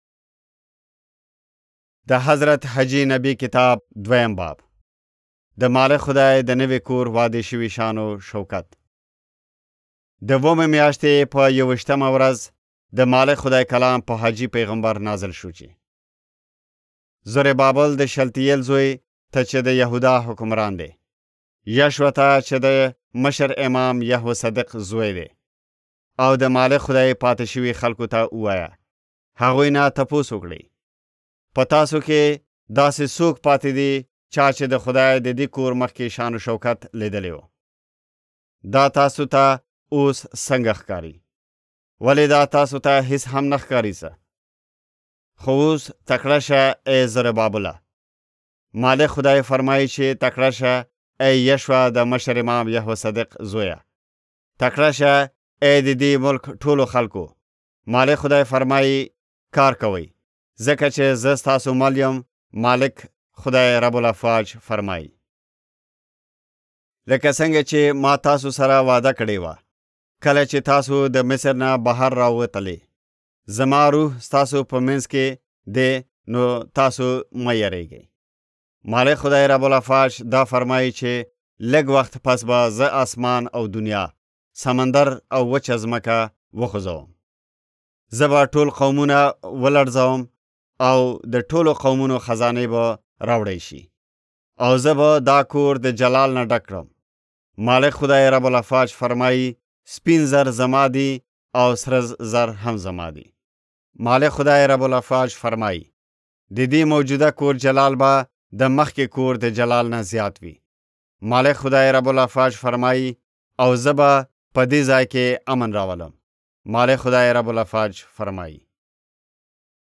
Haggai Audiobook (YZ) – Pashto Zeray
يوسفزئ ختیځ افغانستان ختیځ افغانستان